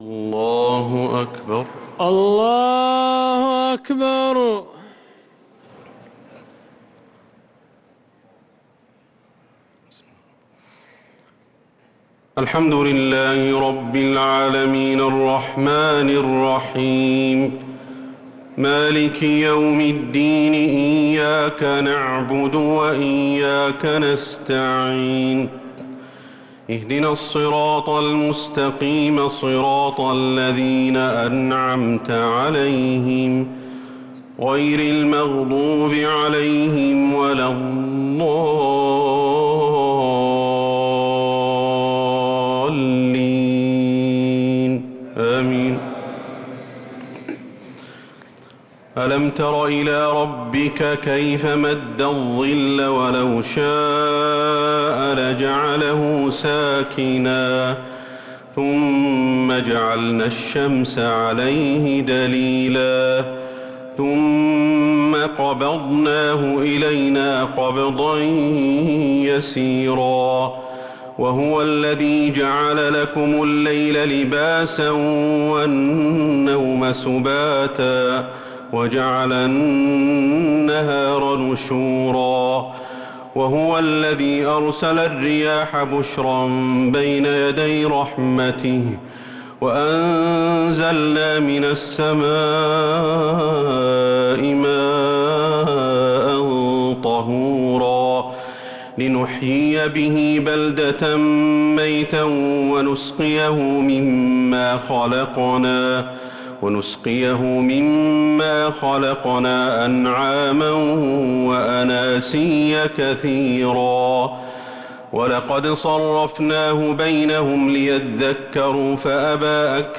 تهجد ليلة 22 رمضان 1446هـ من سورة الفرقان (45-77) و الشعراء (1-104) | Tahajjud 22nd night Ramadan1446H Surah Al-Furqan and Al-Shua’ara > تراويح الحرم النبوي عام 1446 🕌 > التراويح - تلاوات الحرمين